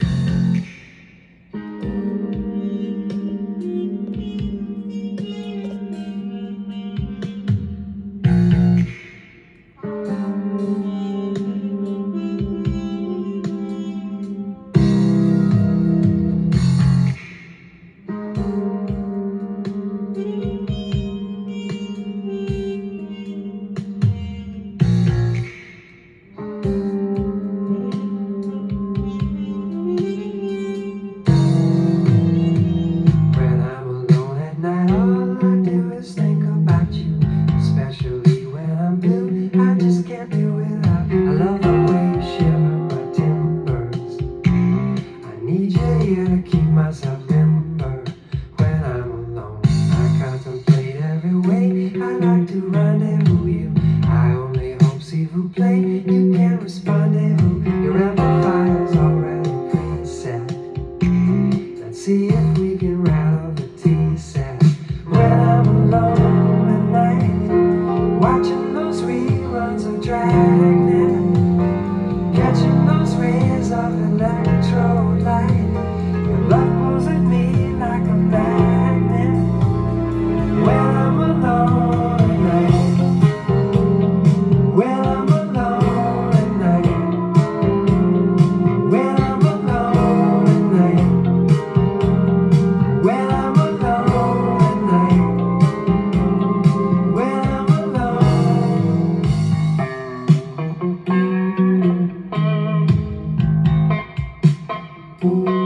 ジャンル：AOR
店頭で録音した音源の為、多少の外部音や音質の悪さはございますが、サンプルとしてご視聴ください。